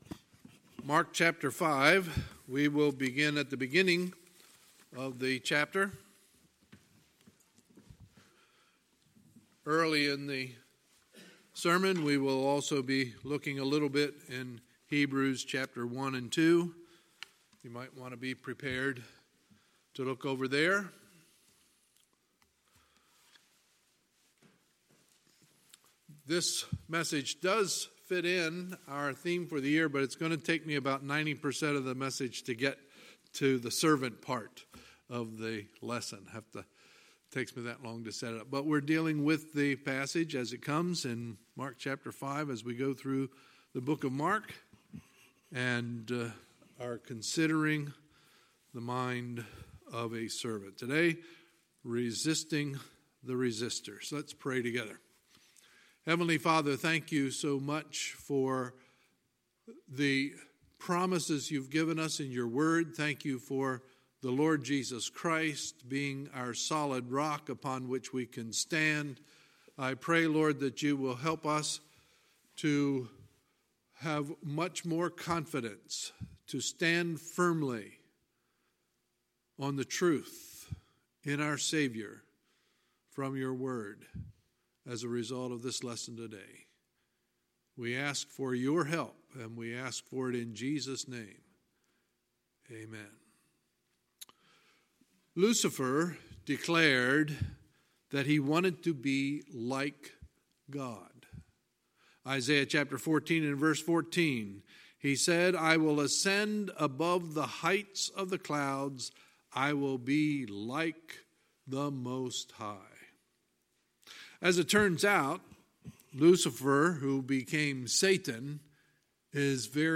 Sunday, April 28, 2019 – Sunday Morning Service